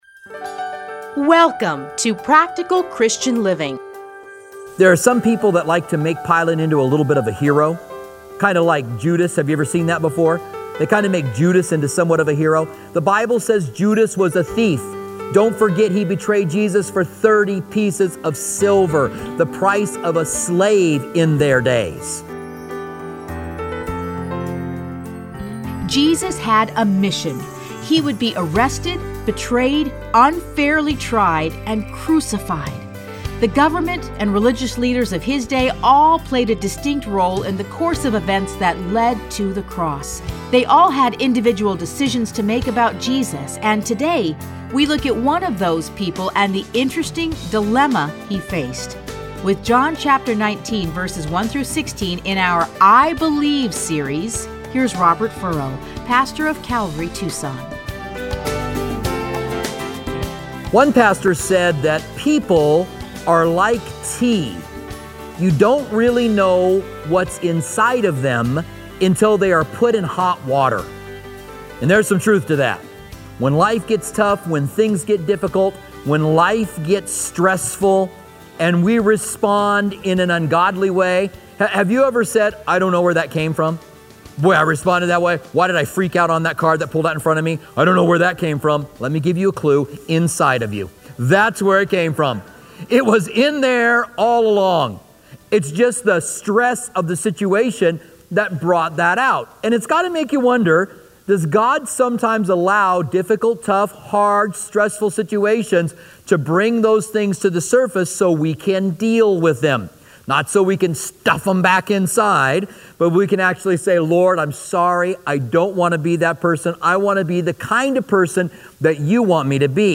Listen to a teaching from John 19:1-6.